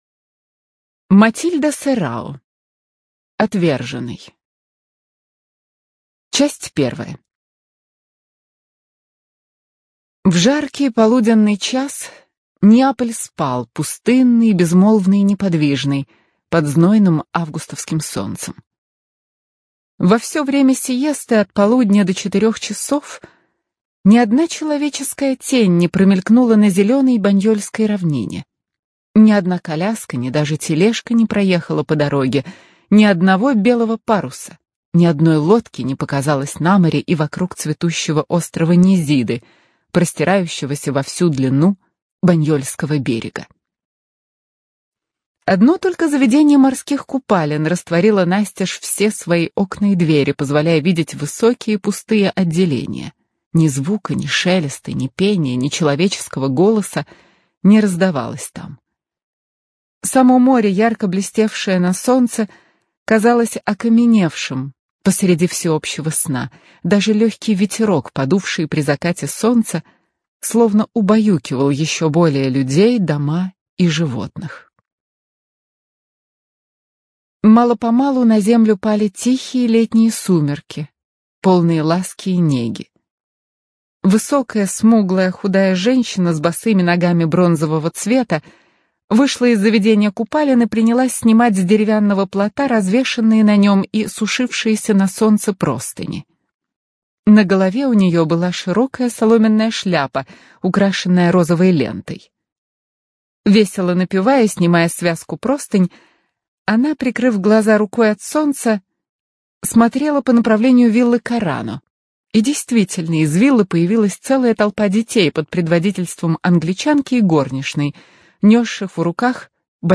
Студия звукозаписиАрдис
Предлагаем вашему вниманию аудиокнигу «Отверженный» – новеллу известной итальянской писательницы и журналистки Матильды Серао (1856–1927).